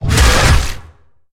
Sfx_creature_squidshark_bite_01.ogg